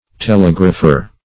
Telegrapher \Te*leg"ra*pher\, n.
telegrapher.mp3